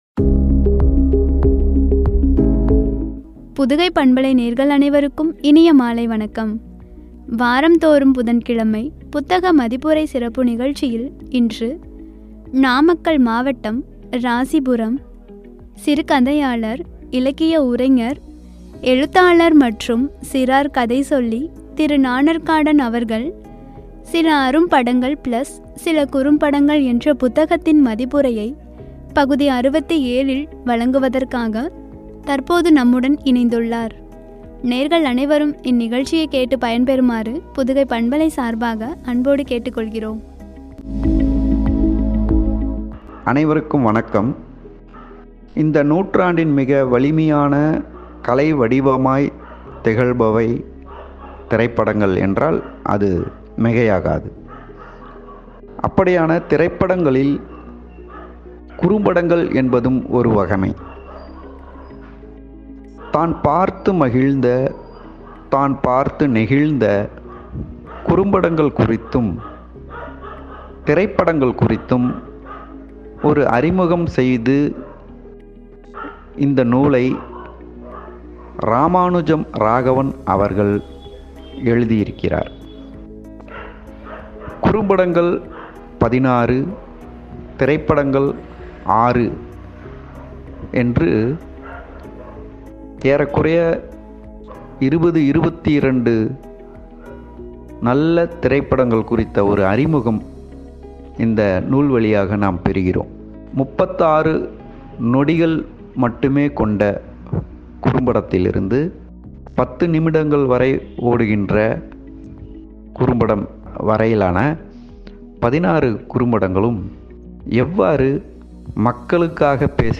குறித்து வழங்கிய உரை.